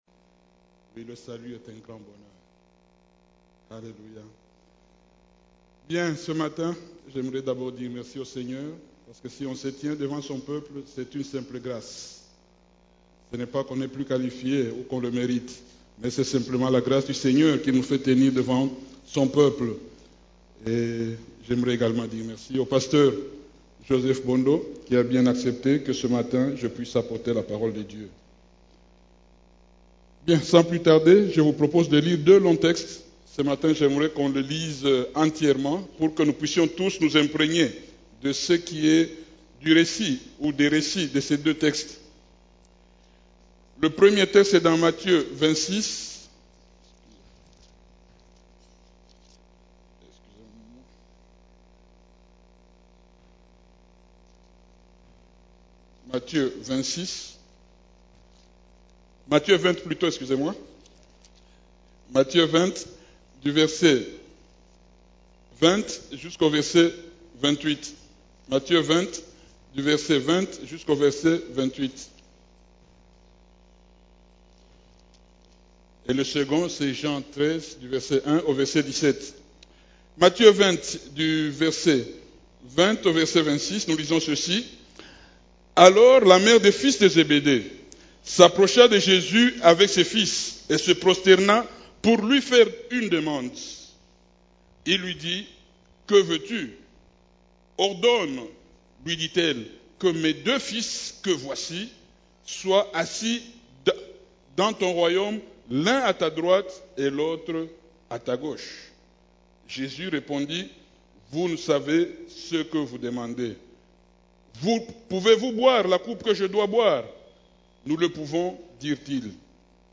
CEF la Borne, Culte du Dimanche, Serviteur, seul poste vacant dans l'Eglise